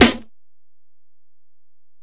Snare (43).wav